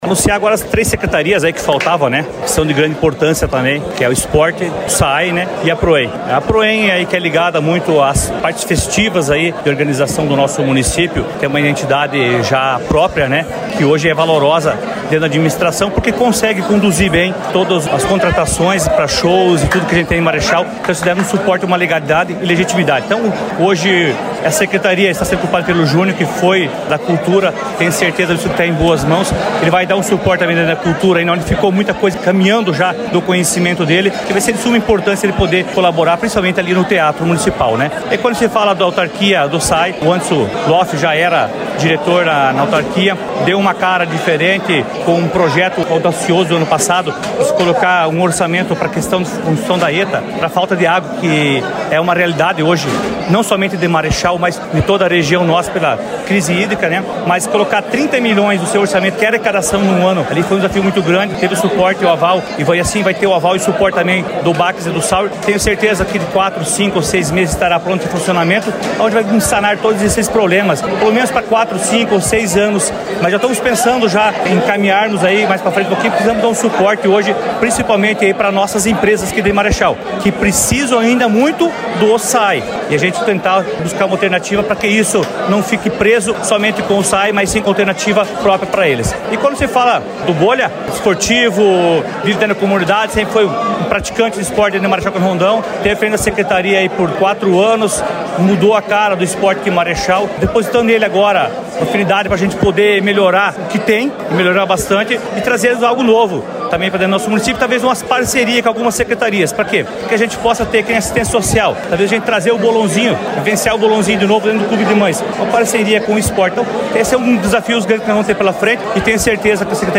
Ouça entervista com o prefeito Adriano Backes